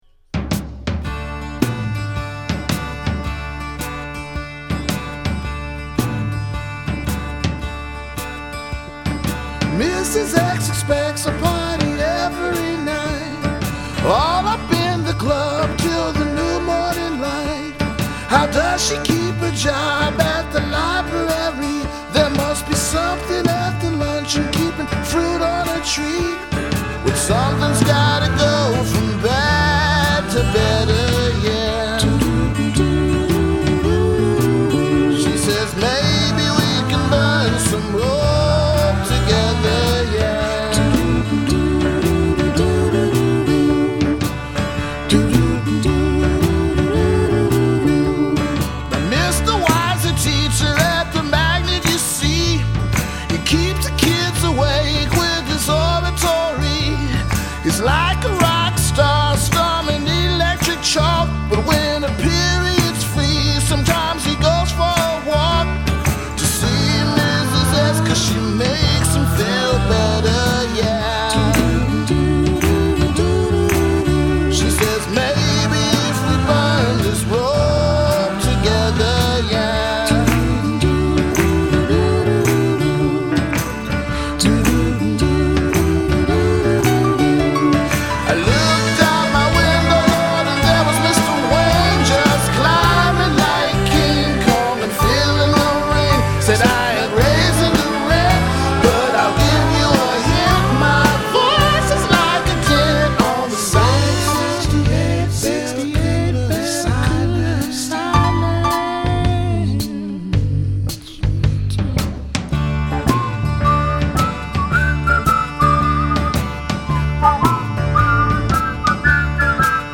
avant garde mashup of pop, R&B and funk